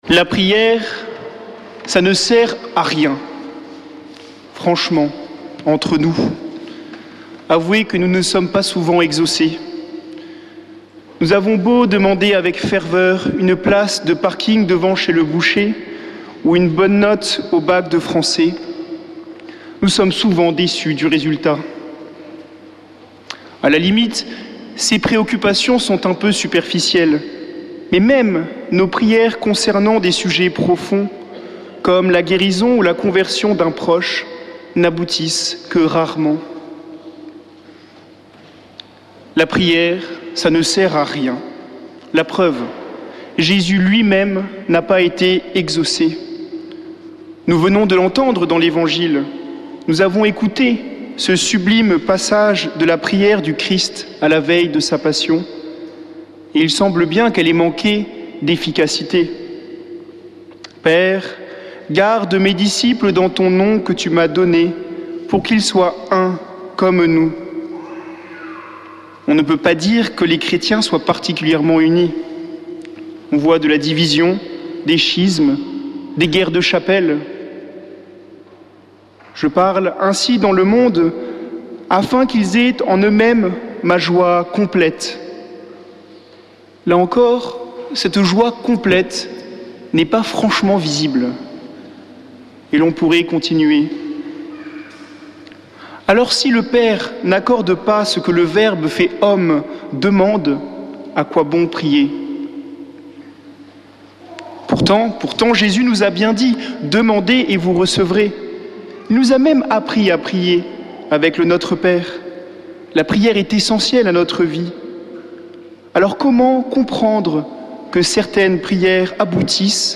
dimanche 12 mai 2024 Messe depuis le couvent des Dominicains de Toulouse Durée 01 h 30 min
Homélie du 12 mai